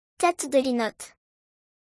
\Tet – Duh – Lee – Nut\